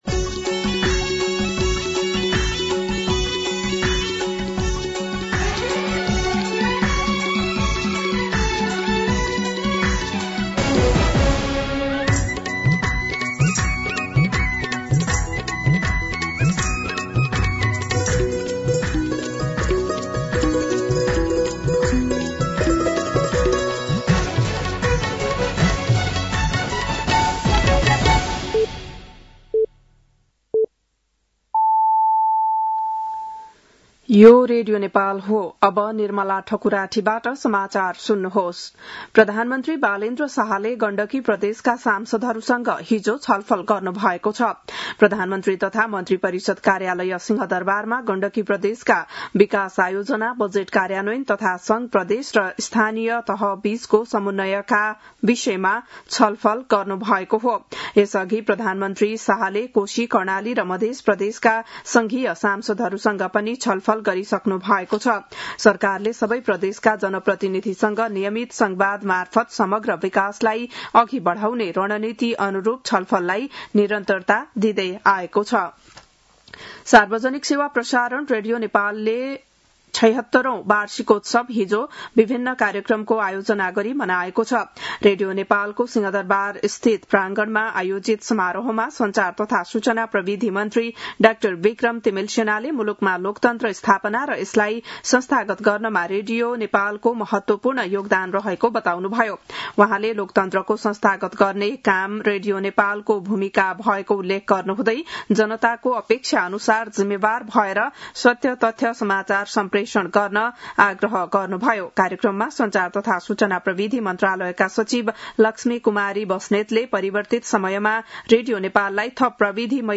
बिहान ११ बजेको नेपाली समाचार : २१ चैत , २०८२